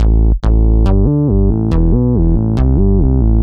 Index of /musicradar/french-house-chillout-samples/140bpm/Instruments
FHC_MunchBass_140-A.wav